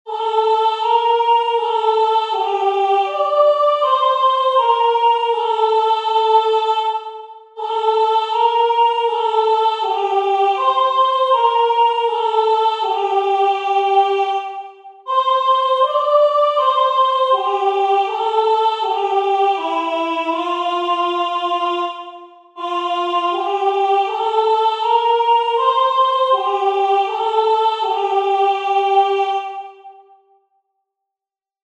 Skaņdarbs lieliski piemērots senās mūzikas praktizēšanai, visas balsis dzied vienā ritmā.
O.Salutaris-Tenor.mp3